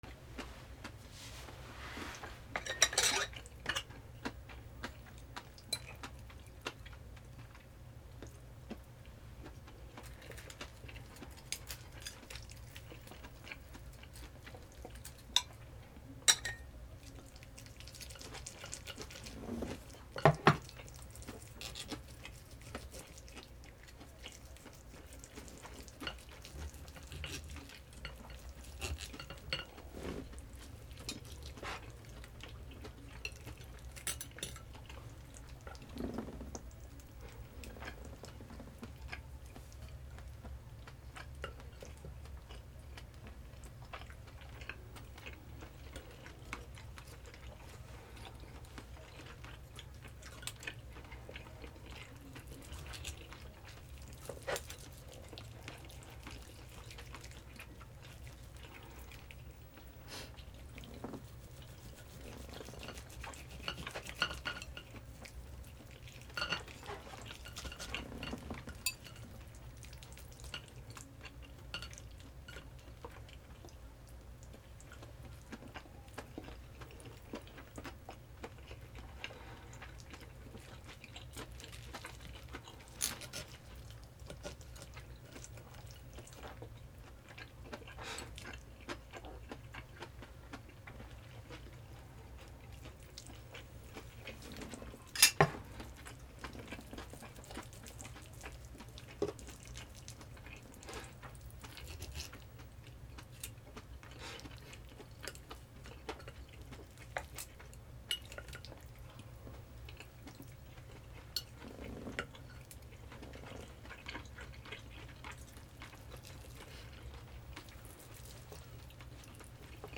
プラスチック食器で食事 2